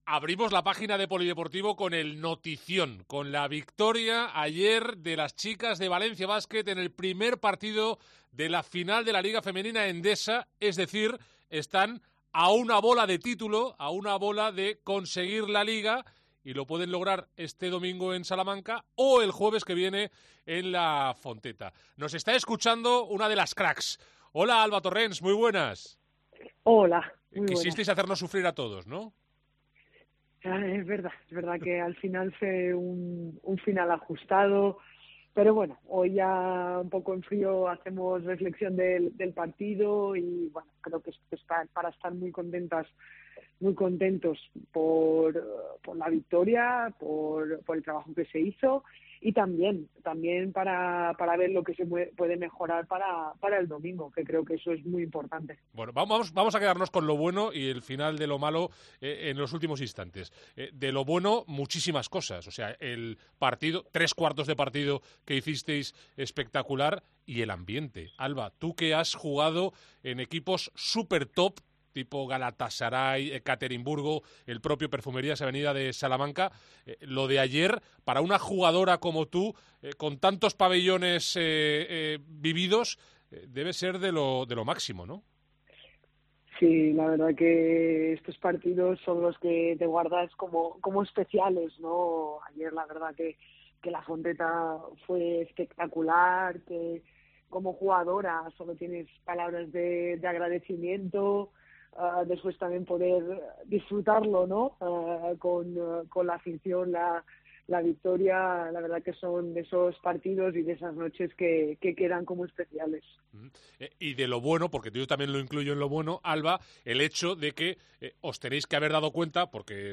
ENTREVISTA COPE
AUDIO. Entrevista a Alba Torrens en Deportes COPE Valencia